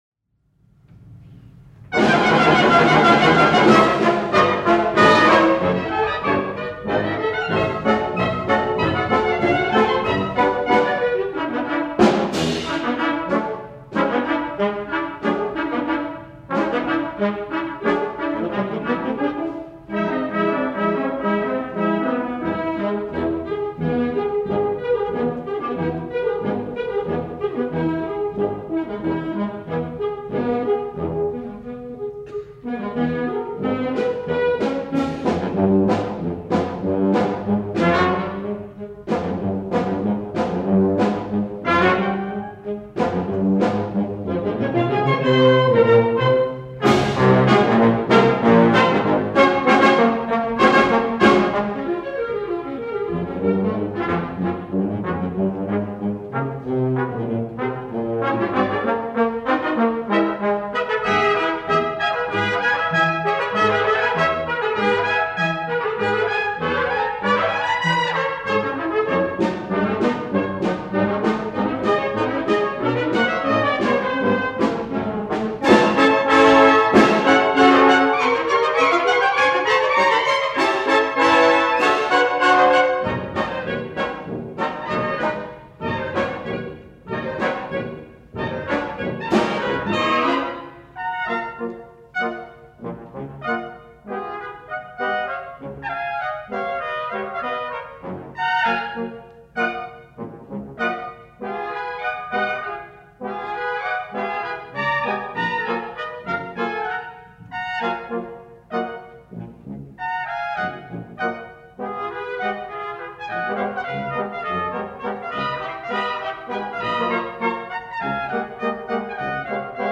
Concert Performance October 7, 1973
Audience nearly filled the house.
using a half-track, 10” reel-to-reel Ampex tape recorder
Armstrong Auditorium, Sunday at 4:00 PM